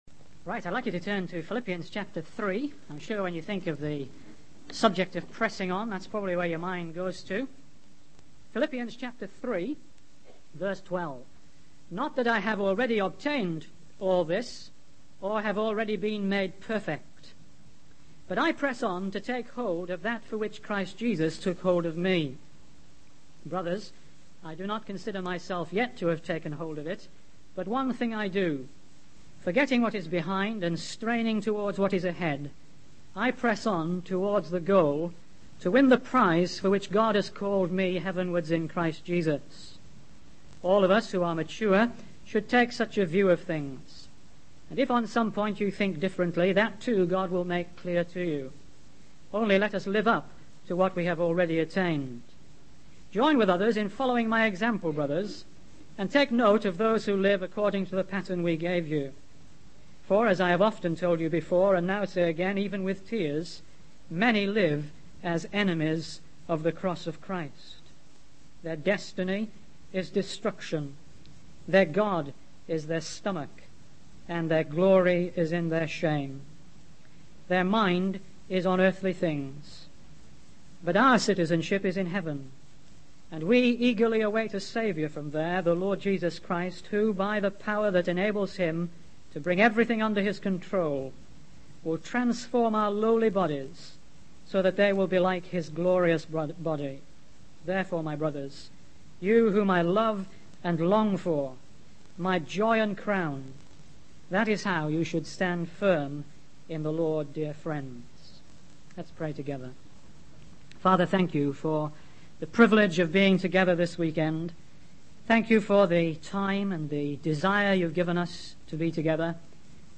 In this sermon, the speaker challenges the audience to examine their Christian lives and determine if they are truly straining towards what lies ahead. He emphasizes the importance of daily progress and not dwelling on past accomplishments. The speaker uses the parable of the man who found treasure in a field to illustrate the need to prioritize spiritual growth over worldly desires.